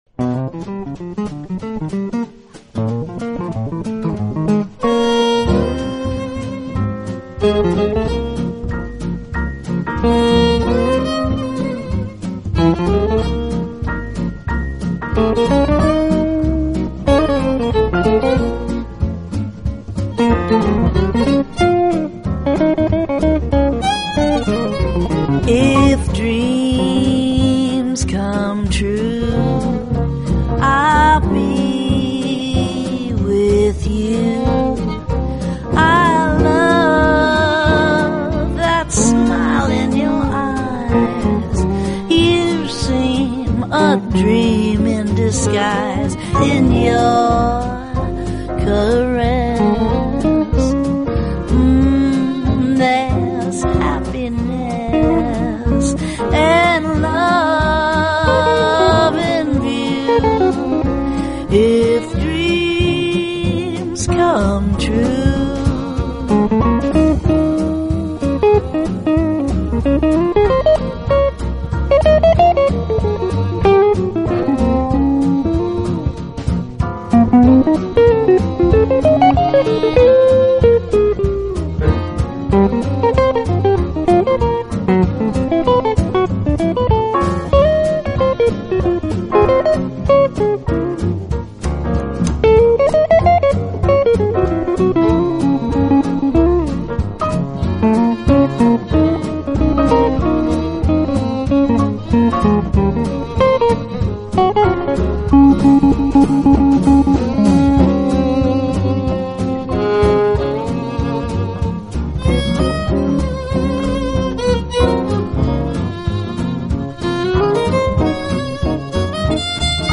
略带低沉柔美的女中音，轻吟浅唱，风情万千，10首醉人蓝调情歌，组成
变化。她出色的真假音切换唱法让人着迷，声音稍为带点沧桑、沙哑，所以
波就毫无压力，阵阵缓缓地铺满空间；像钢琴就随着指尖的触键力道粒粒清
晰地弹跳着；像电吉他的弦也紧随着指腹的捺、压，拨奏出一个又一个音符。